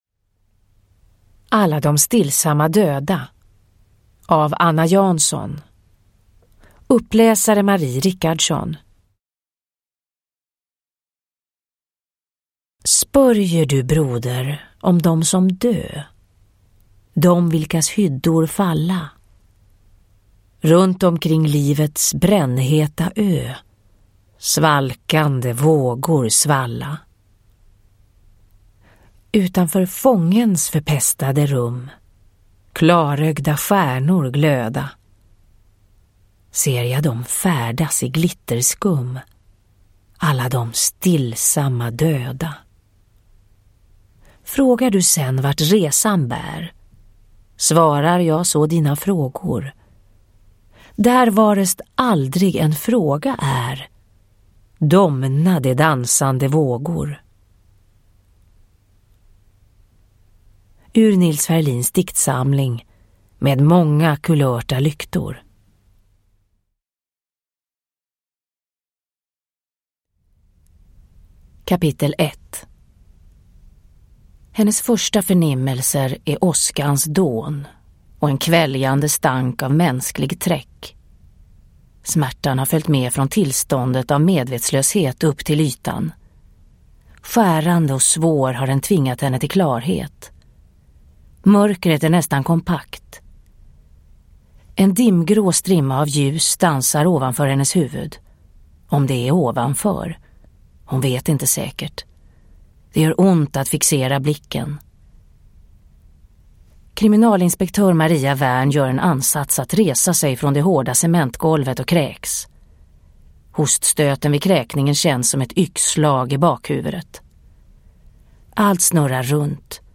Alla de stillsamma döda – Ljudbok – Laddas ner
Uppläsare: Marie Richardson